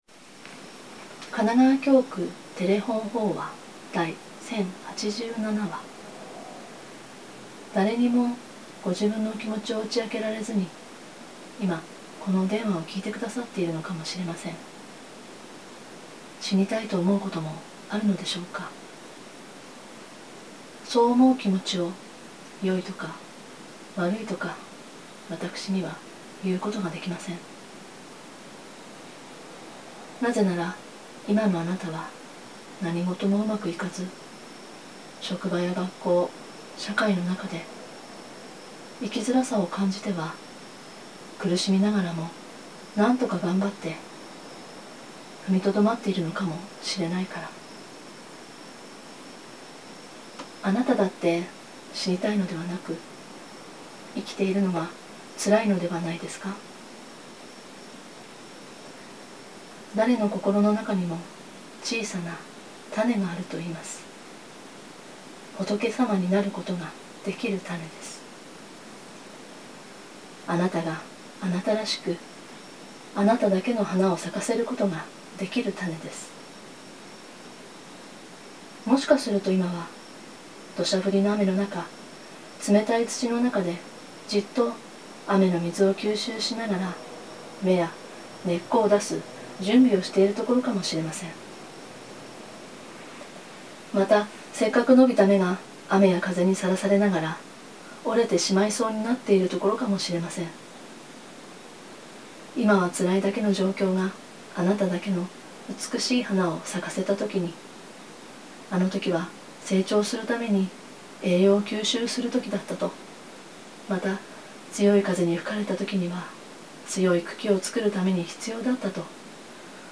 テレホン法話